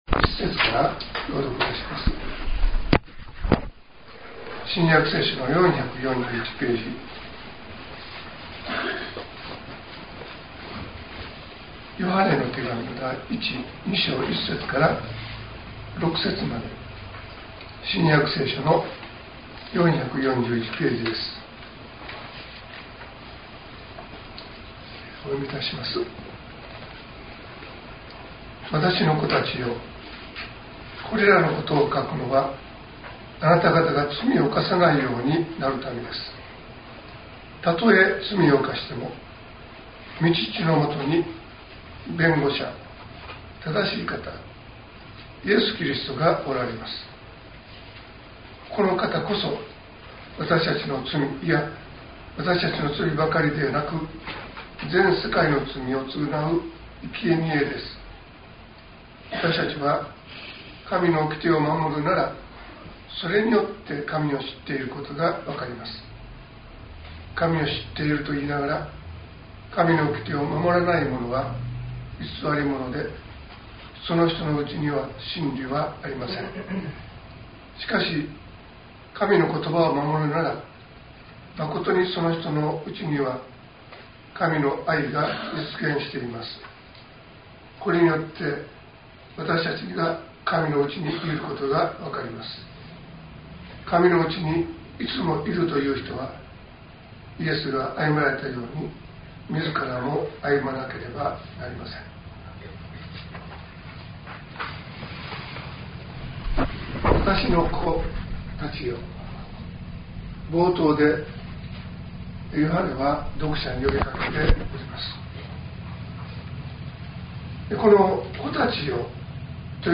2025年05月18日朝の礼拝「弁護者イエス・キリスト」西谷教会
音声ファイル 礼拝説教を録音した音声ファイルを公開しています。